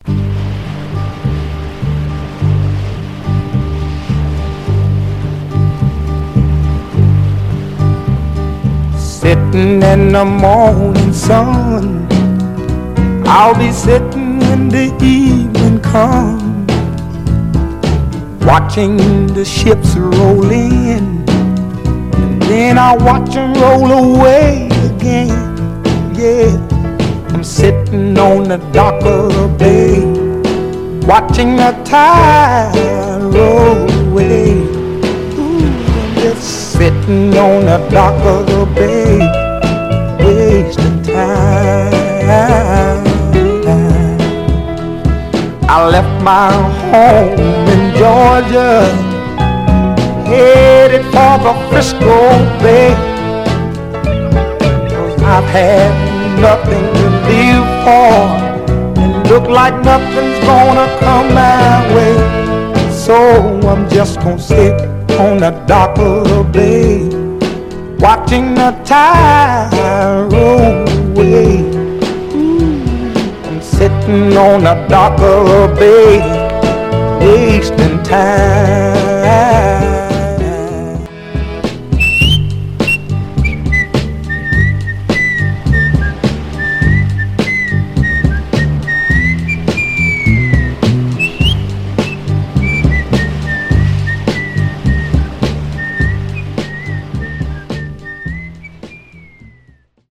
ヴァイナルでキレイに鳴る7インチ・シングルはさすがにあまり見かけなくなってきました。
※イントロやフェードアウト部でザーッという音が入りますが、これは波音の演出で、元々の楽曲によるものです。
※試聴音源は実際にお送りする商品から録音したものです※